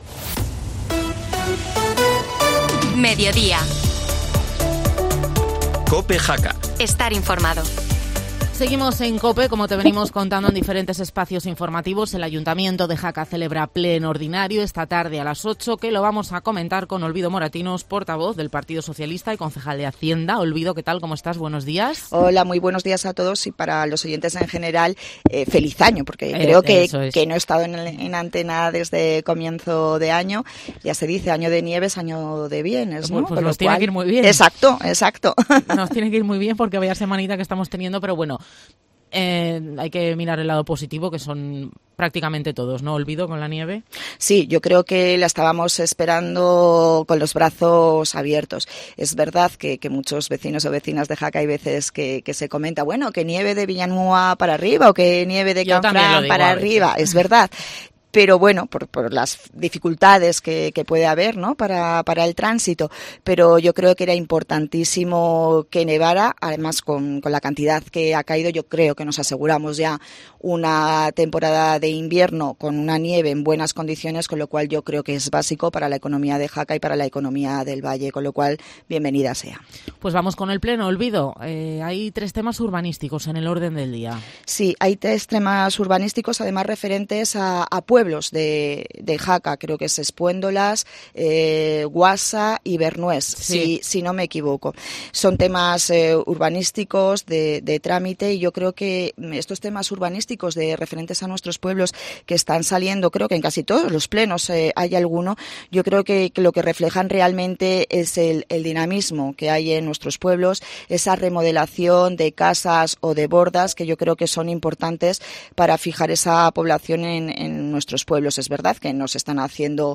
Audio Olvido Moratinos, concejal Hacienda